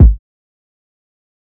TC Kick 10.wav